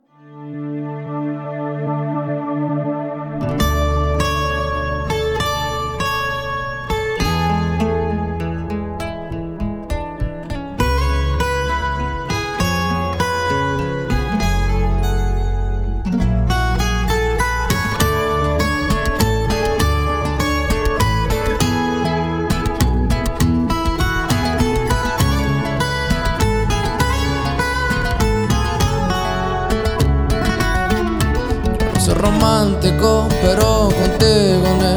Música Mexicana, Latin